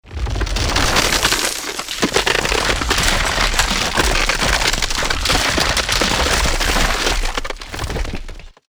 Природные явления звуки скачать, слушать онлайн ✔в хорошем качестве